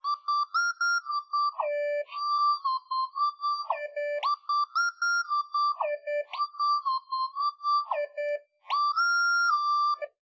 Simulated recordings with 4cm microphone spacing and 130ms reverberation time